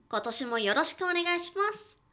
ボイス
キュート女性挨拶